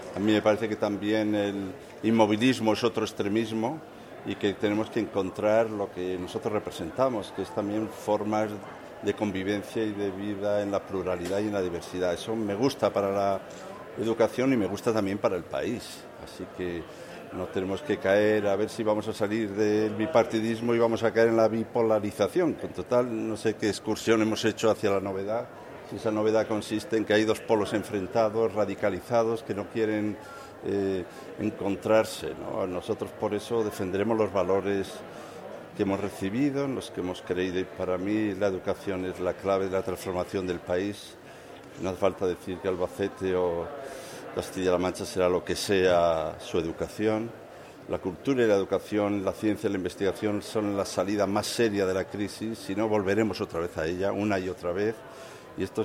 El ex ministro socialista de Educación, Ángel Gabilondo, ha expuesto en la Filmoteca de Albacete las propuestas socialistas en materia de Educación.
Cortes de audio de la rueda de prensa